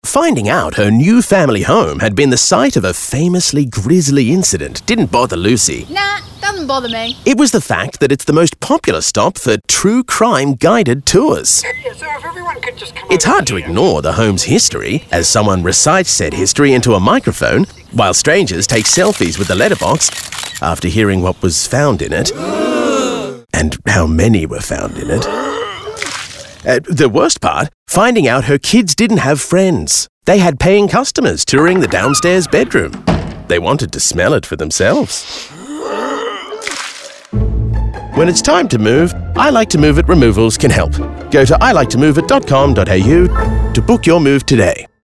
2024-When-its-time-to-move-Bodybuilder-Broadcast-Audio-MP3